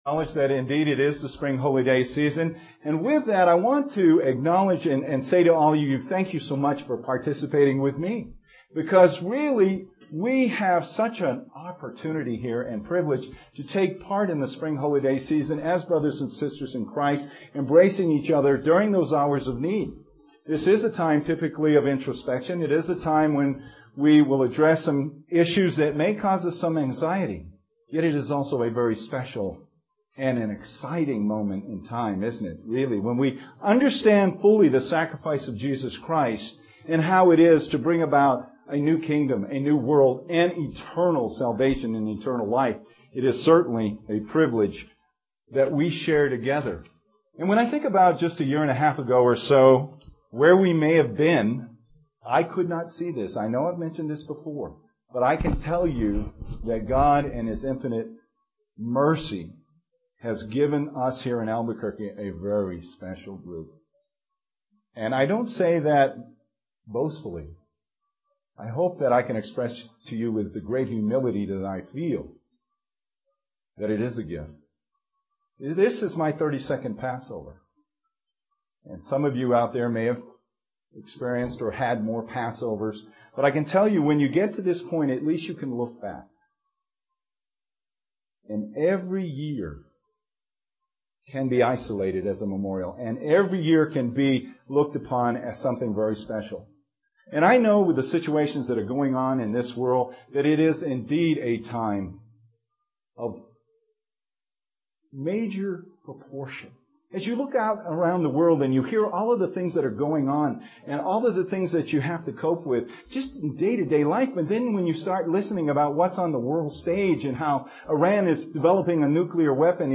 UCG Sermon Studying the bible?
Given in Albuquerque, NM